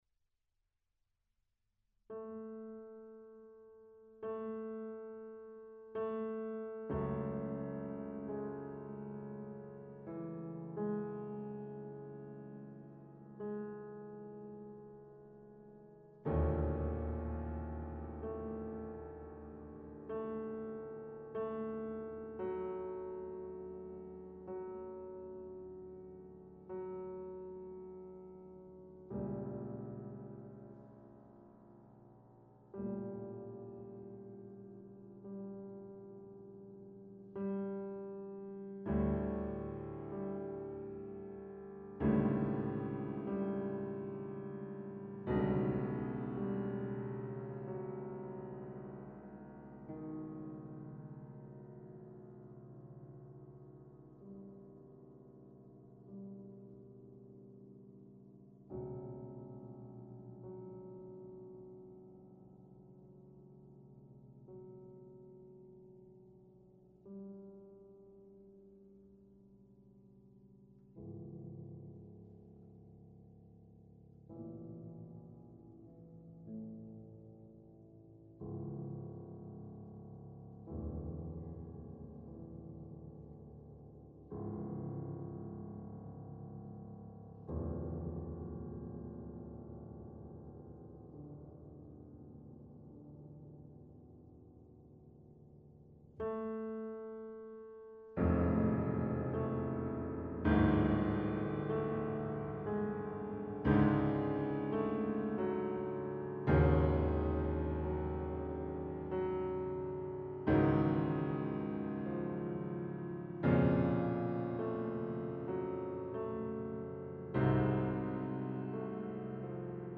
for 4 Voices